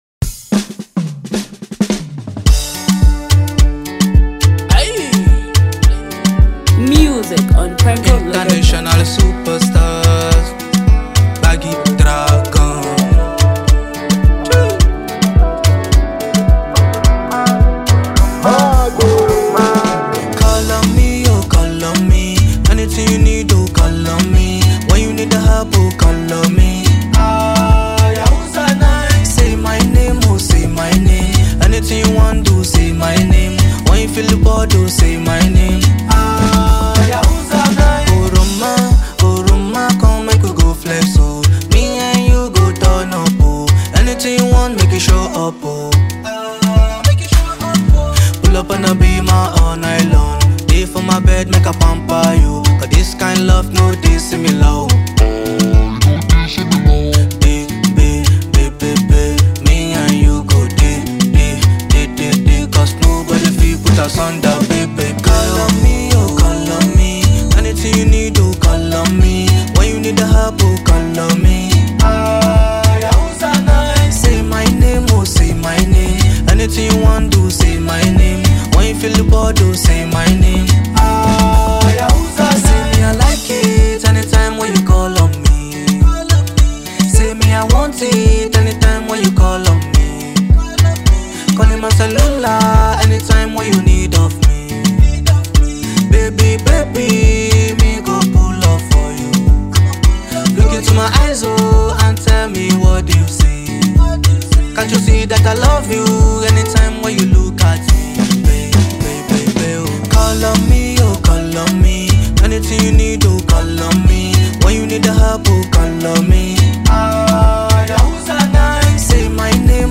melodious song